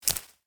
Minecraft Version Minecraft Version 1.21.5 Latest Release | Latest Snapshot 1.21.5 / assets / minecraft / sounds / block / cactus_flower / break4.ogg Compare With Compare With Latest Release | Latest Snapshot
break4.ogg